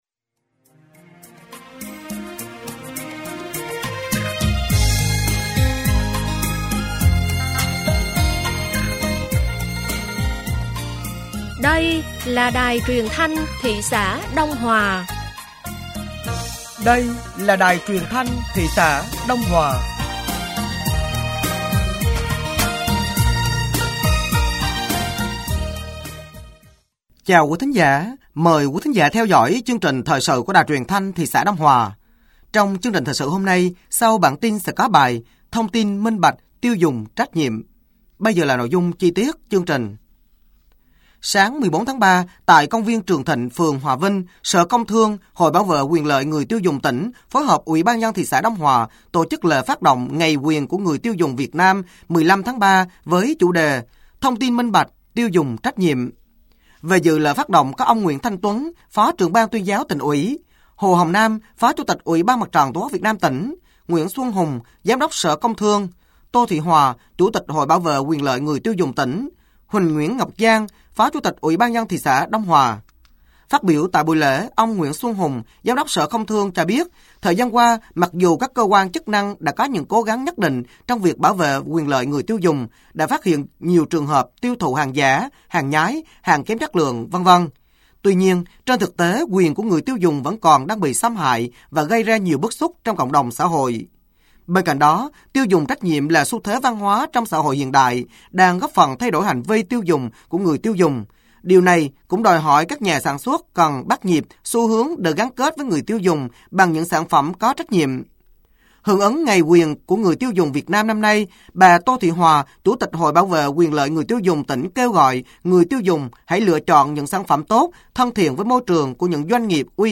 Thời sự tối ngày 14 và sáng ngày 15 tháng 3 năm 2025